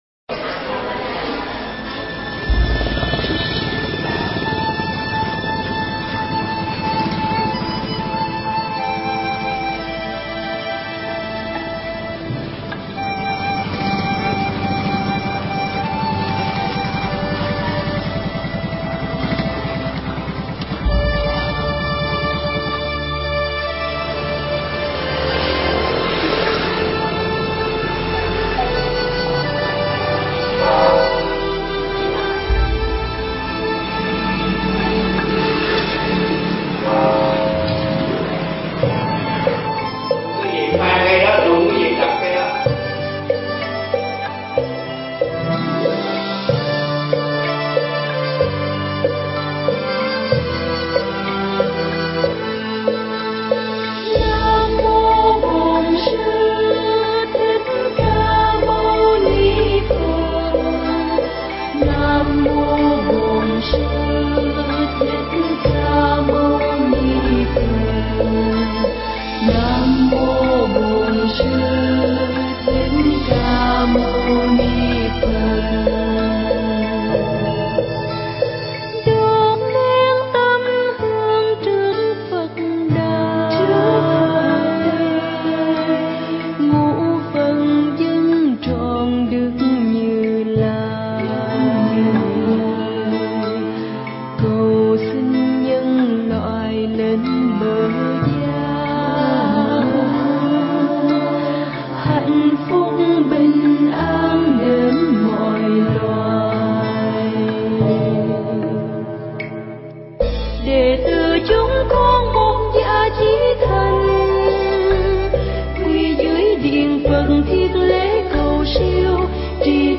Nghe Mp3 thuyết pháp Thay Tâm Đổi Tánh